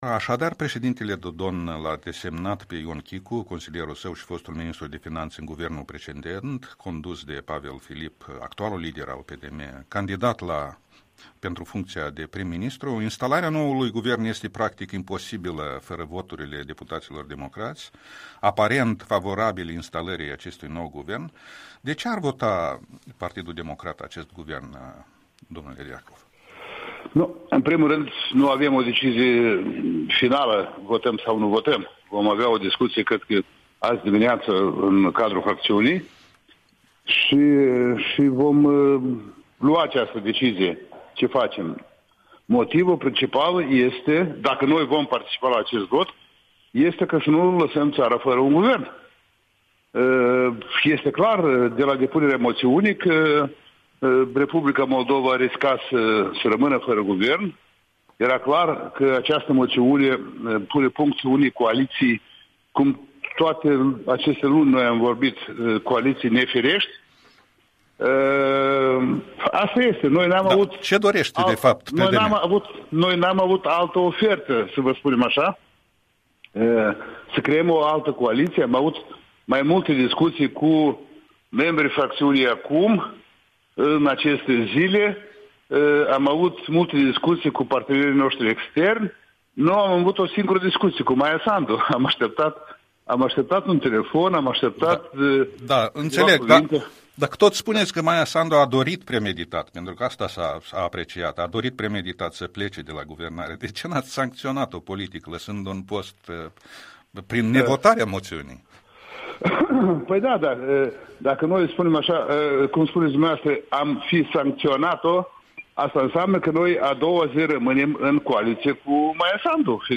Interviul matinal al Europei Libere, 14 noiembrie 2019